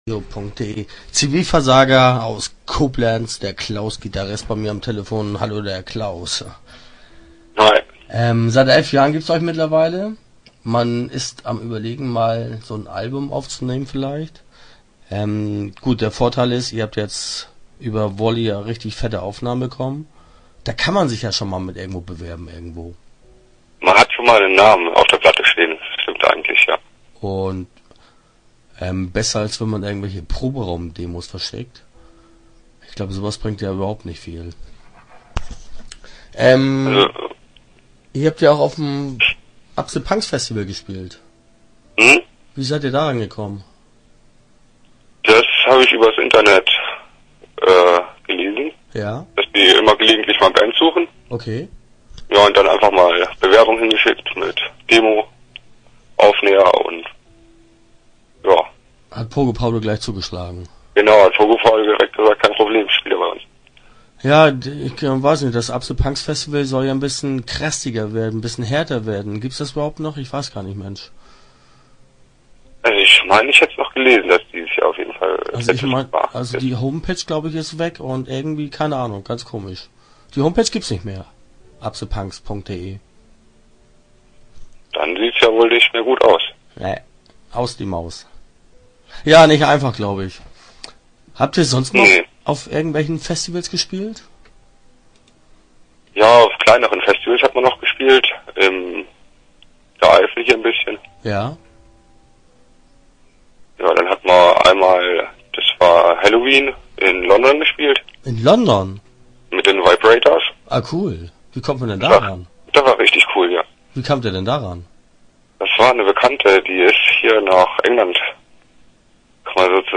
Start » Interviews » Zivilversager